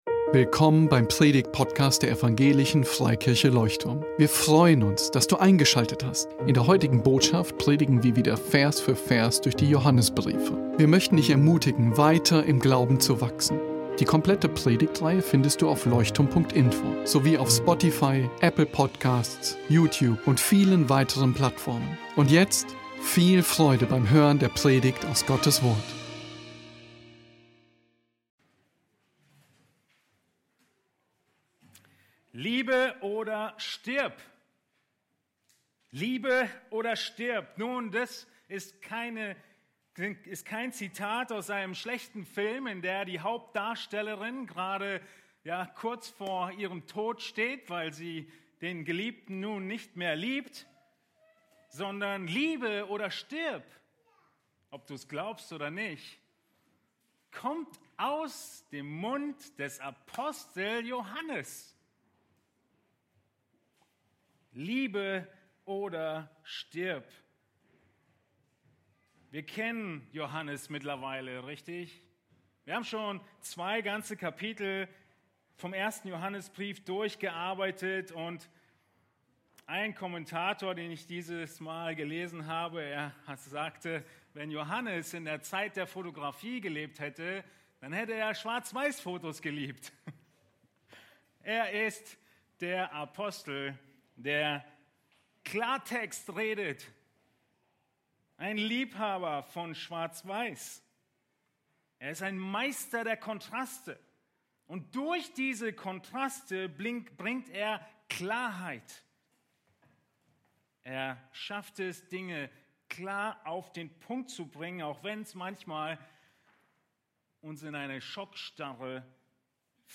Predigtgliederung Sei auf der Hut vor Mord Sei auf der Hut vor Hass Der Beitrag 1.